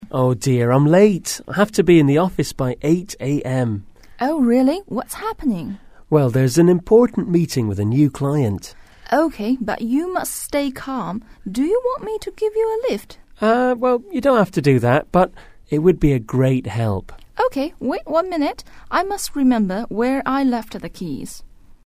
英语初学者口语对话第28集：要不要我捎你一程？
english_39_dialogue_1.mp3